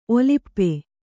♪ olippe